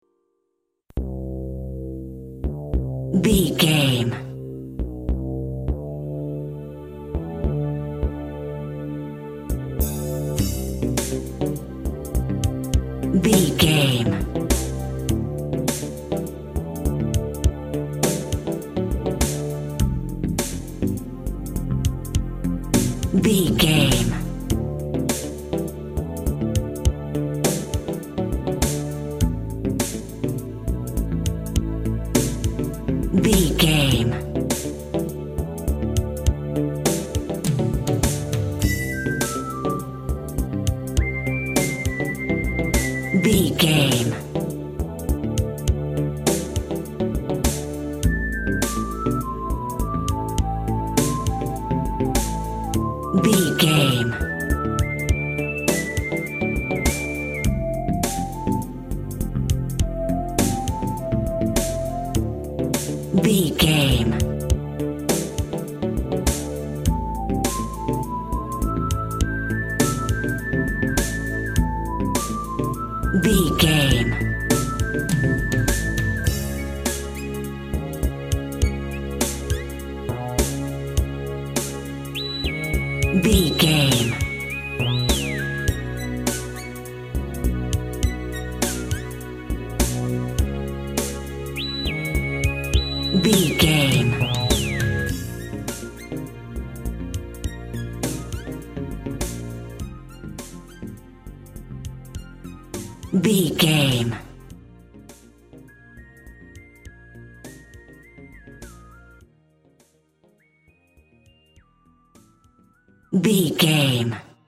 Aeolian/Minor
Slow
groovy
smooth
futuristic
industrial
drums
bass guitar
electric guitar
synthesiser
Retro
pop
electronic
80s music
synth bass
synth lead